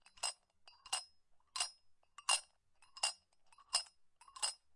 描述：在隔音音箱中录制，使用金属纪念品创造声音。
Tag: 金属 驰骋